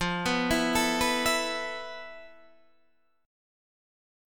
FM7b5 chord